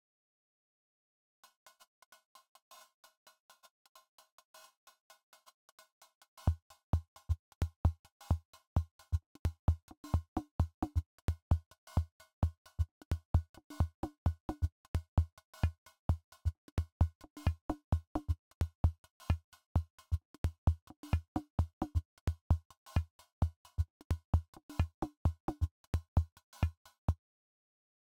A little “can it hat” experiment—this is all UT impulse into FX block, not sure if pinging analog track filters is allowed in the spirit here, but posting anyway
The hat is an impulse-pinged fm’ed filter into reverb only, reverb into fx track with 2 notch phaser, then gated with the amp envelope set to ADSR.
Straight from ST to phone, so it’s on the quiet side.